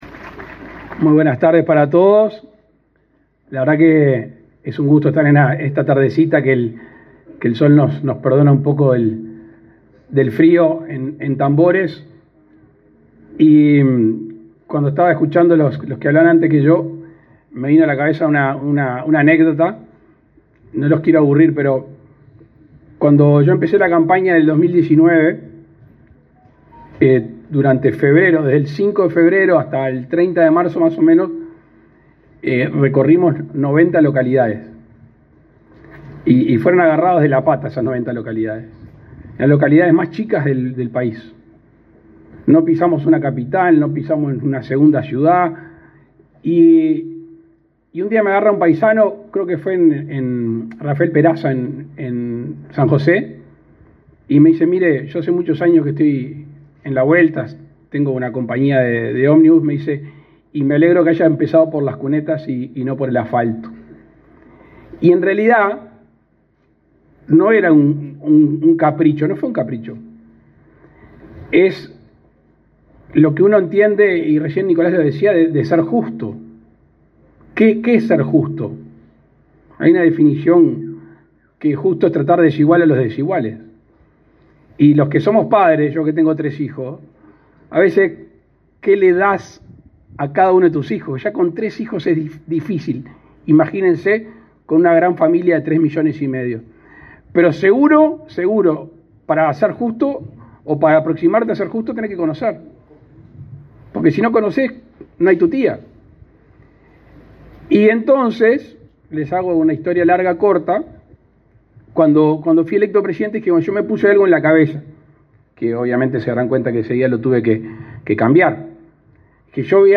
Palabras del presidente de la República, Luis Lacalle Pou
Palabras del presidente de la República, Luis Lacalle Pou 27/06/2024 Compartir Facebook X Copiar enlace WhatsApp LinkedIn El presidente de la República, Luis Lacalle Pou, inauguró, este jueves 27, la ampliación de la policlínica de la Administración de los Servicios de Salud del Estado, ubicada en la localidad de Tambores, departamento de Tacuarembó.